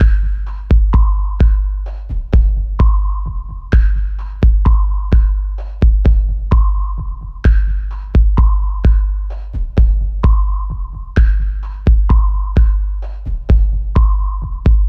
01 drums B.wav